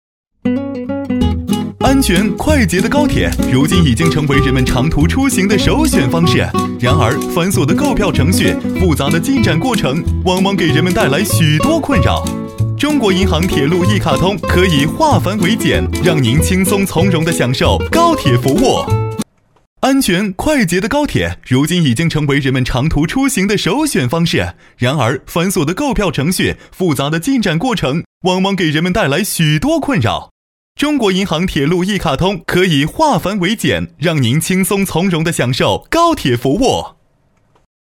飞碟说-男14-出行.mp3